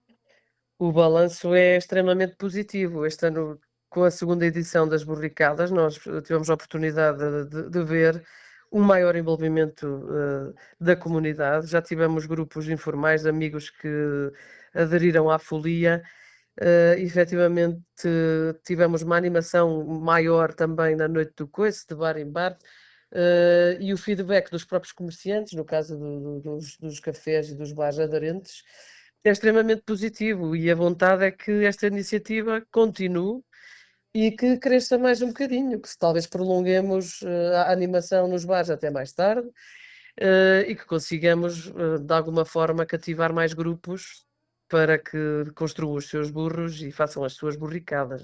A vice-presidente da Câmara Municipal, Maria Manuel Silva, destaca o crescimento da participação e o impacto sentido ao longo dos dias de festa: